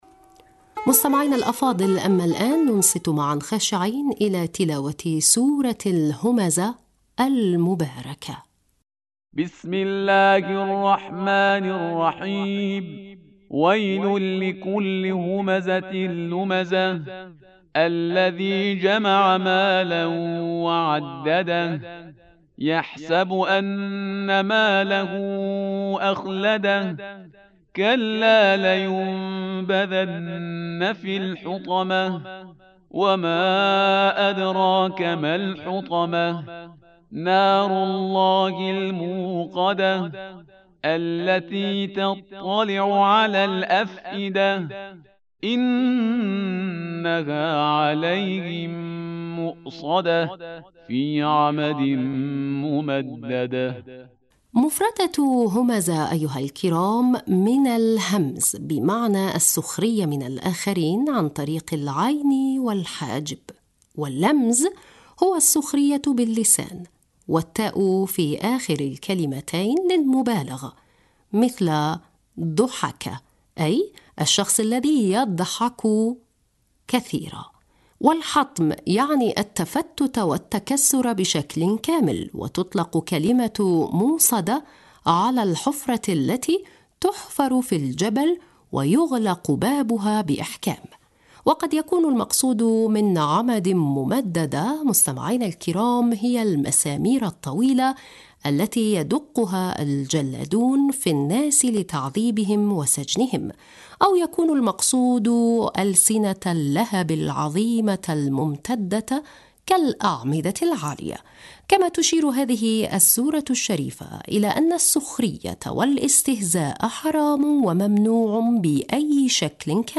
مستمعينا الأفاضل، أما الآن ننصت معاً خاشعين الى تلاوة سورة الهمزة المباركة..